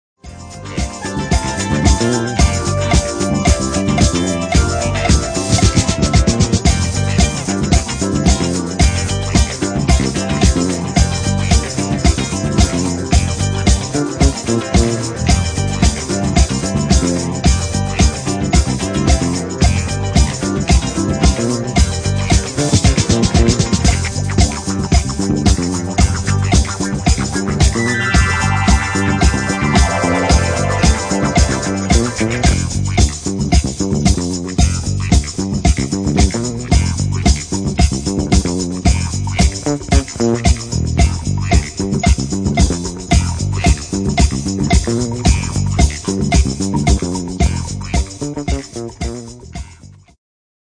Genere:   Disco | Soul | Funky